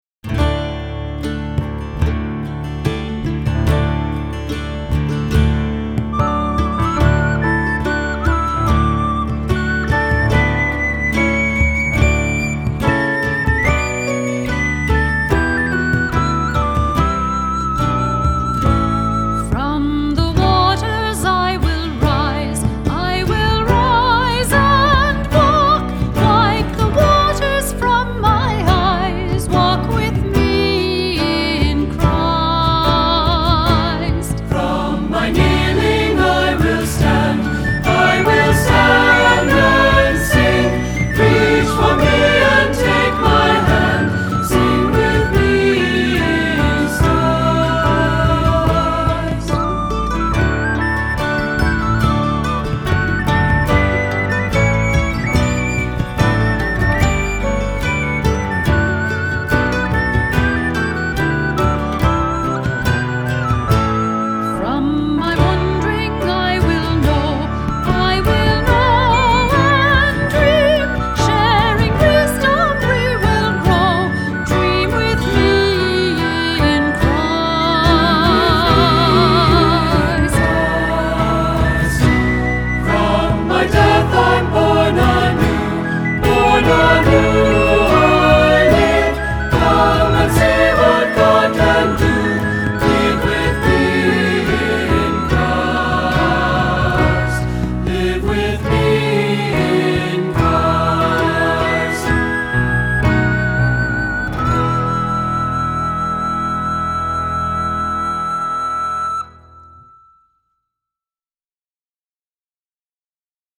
Accompaniment:      Keyboard
Music Category:      Christian
The C instrument part is optional.